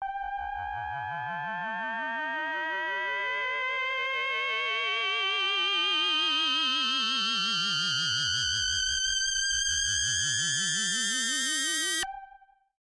标签： MIDI-速度-96 FSharp7 MIDI音符-103 赤-AX80 合成器 单票据 多重采样
声道立体声